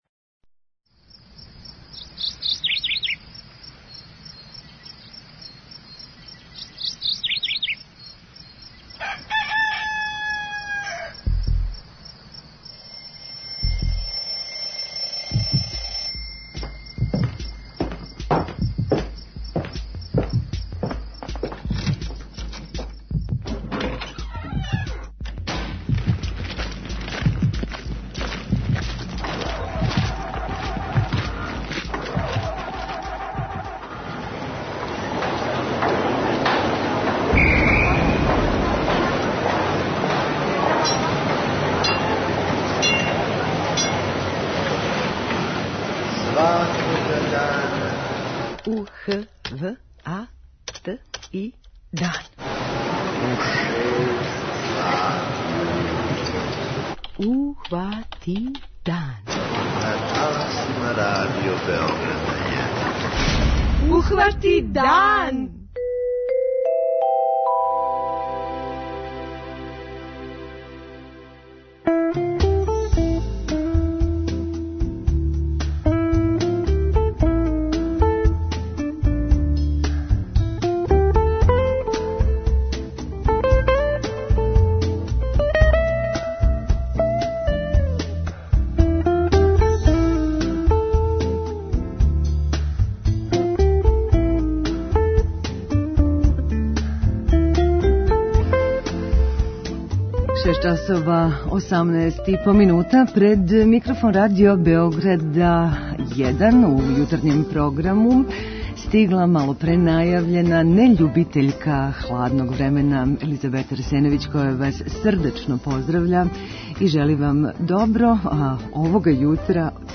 06:30 Догодило се на данашњи дан, 07:00 Вести, 07:05 Добро јутро децо, 08:00 Вести, 08:05 Српски на српском, 08:15 Гост јутра